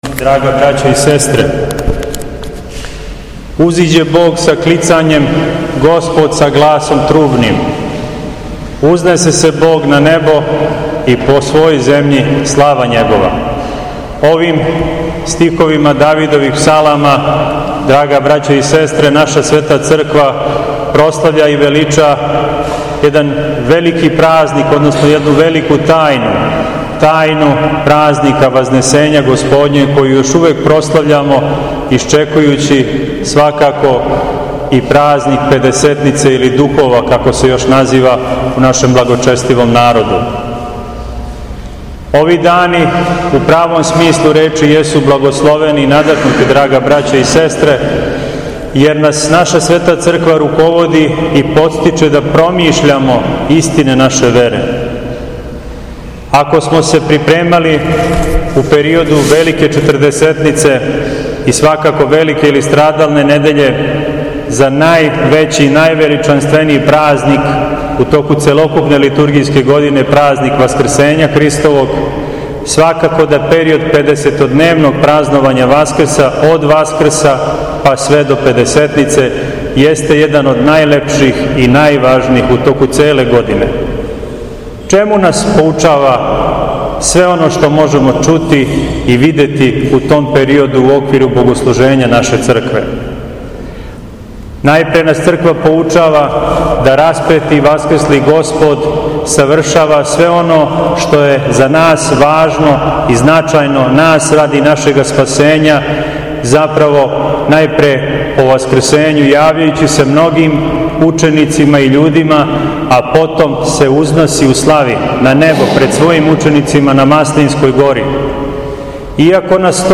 У понедељак седми по Васкрсењу Господњем, када наша Црква прославља светог Талалеја, и светог Стефана Пиперског, Његово Високопреосвештенство Митрополит шумадијски Господин Јован служио је свету архијерејску литургију уз саслуживање професора богословије светог Јована Златоустог, свештенства храма С...
Беседа